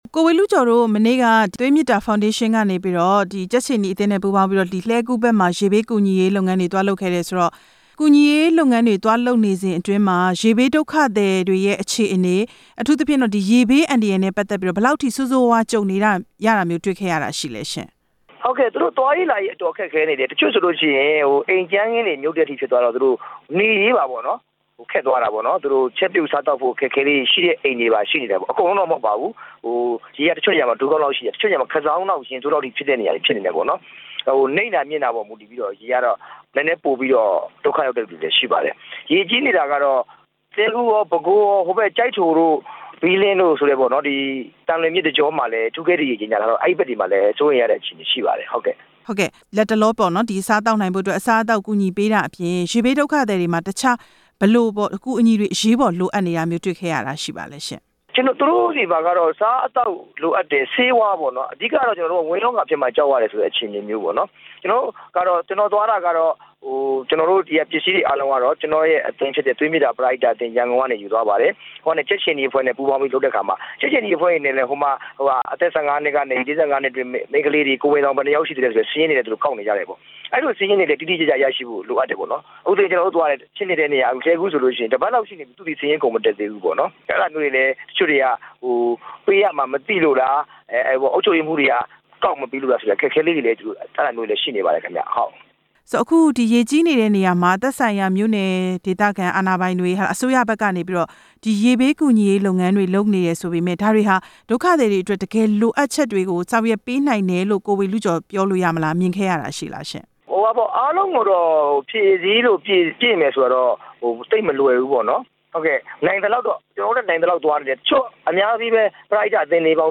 ဦးဝေဠုကျော်နဲ့ မေးမြန်းချက်